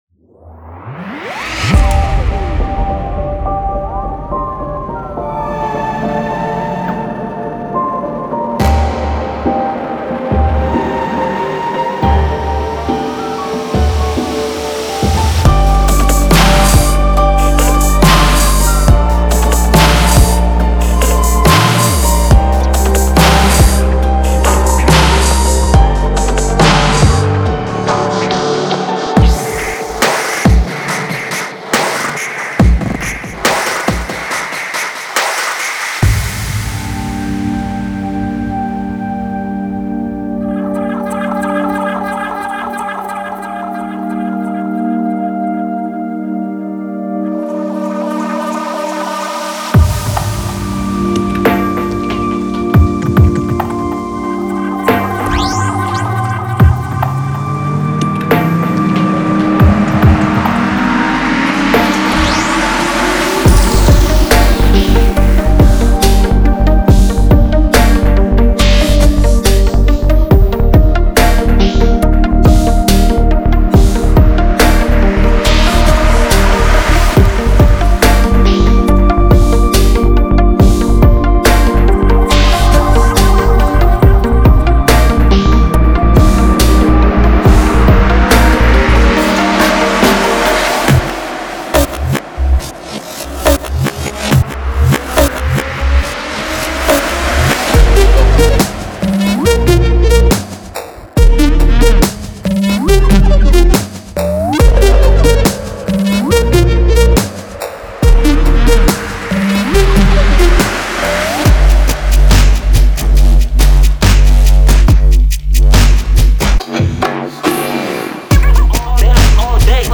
Future BassHip HopTrap
• 40 Slo-Mo Beats
• 40 Synth Loops
• 40 Bass Loops
• 20 Piano Loops
• 20 Vocal Loops